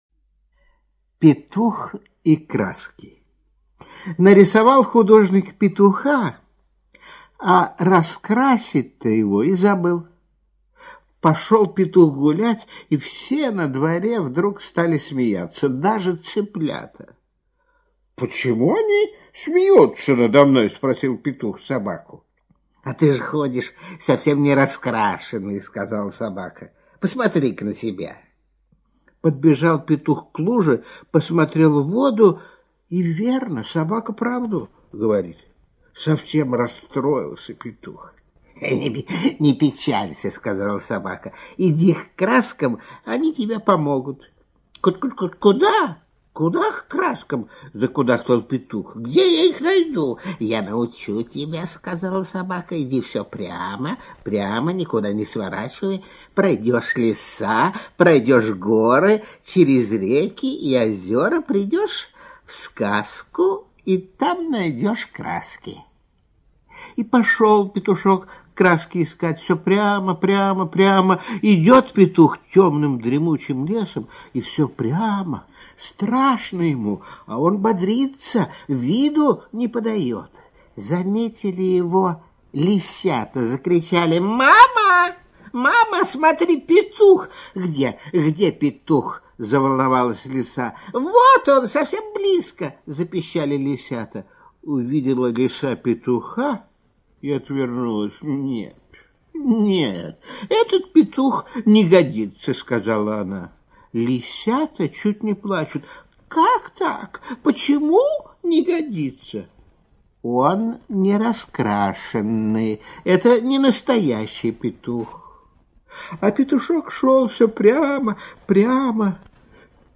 Петух и краски - аудиосказка Сутеева В.Г. Слушайте онлайн сказку "Петух и краски" Сутеева на сайте Мишкины книжки.